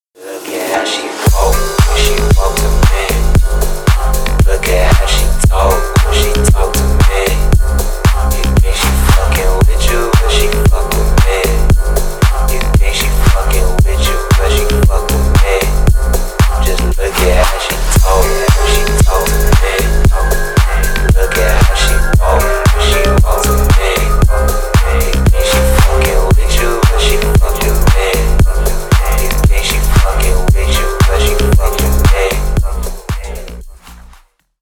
Ремикс # Поп Музыка
громкие